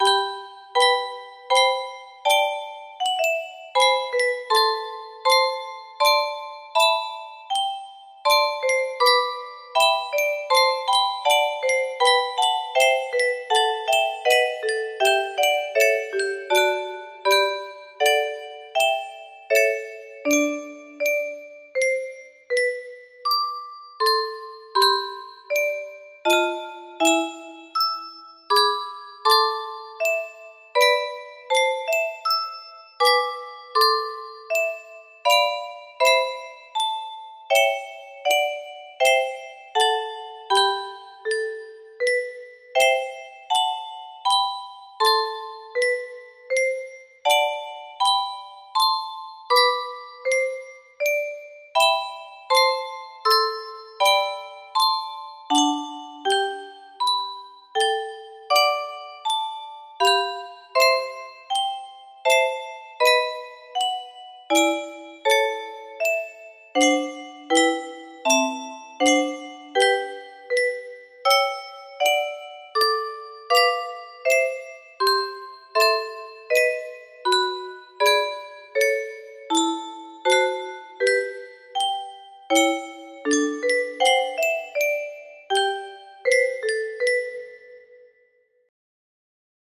fernando sor et.1 music box melody